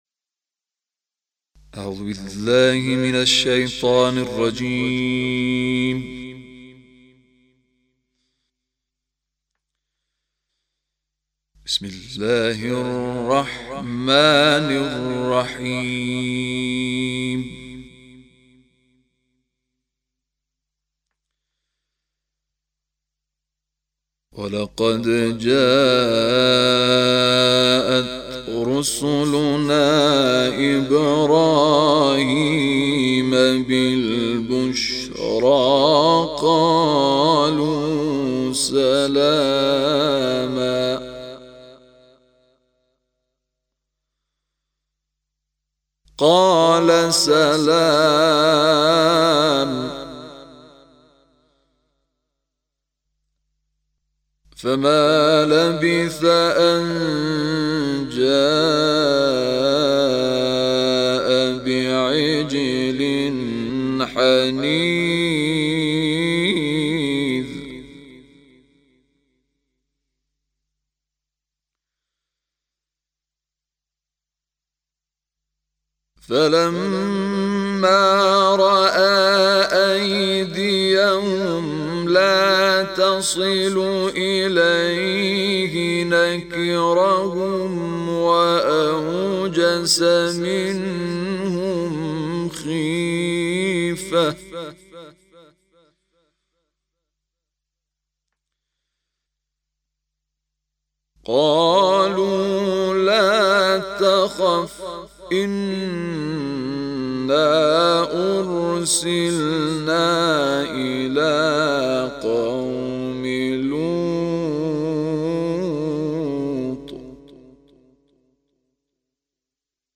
تلاوت استودیویی